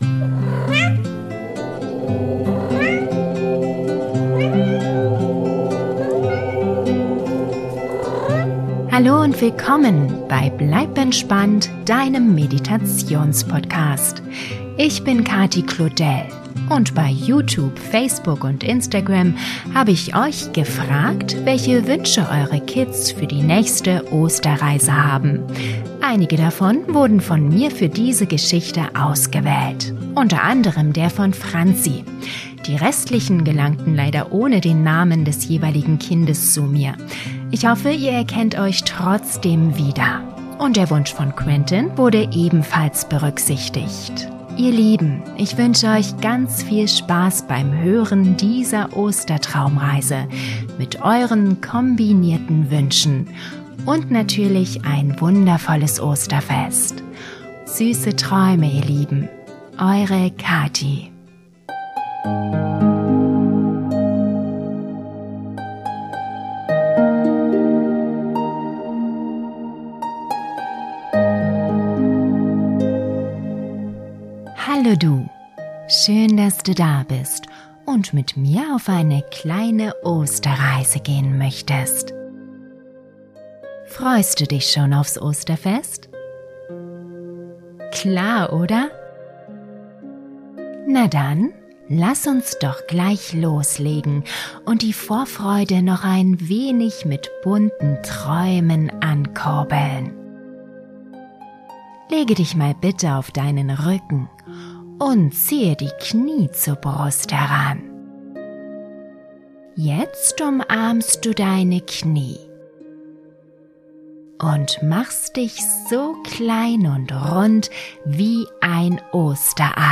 Traumreise für Kinder - Das magische Osterdorf - eine Geschichte über Ostern & Osterhase ~ Bleib entspannt!
In dieser österlich entspannenden Gute Nacht Geschichte führt der Osterhase eure Kinder durch das magische Osterdorf.